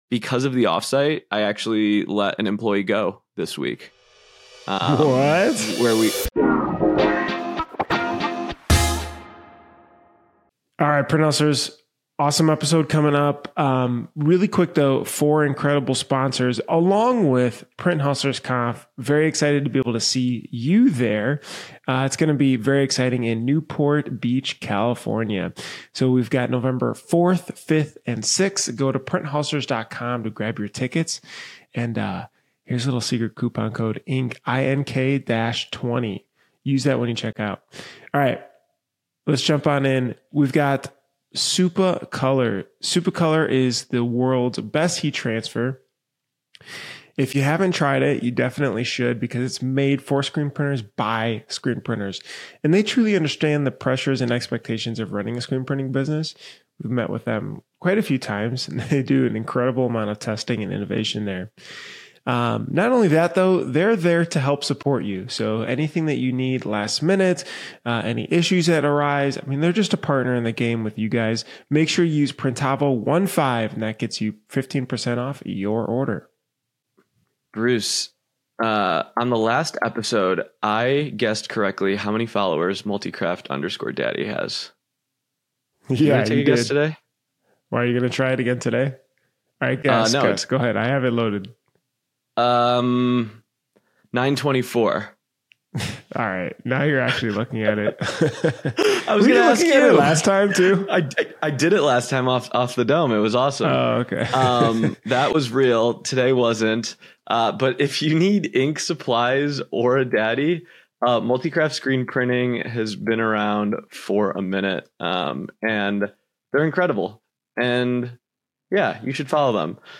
Our hosts chat with us about what an offsite day is, how it works, and the benefits it can bring to the team.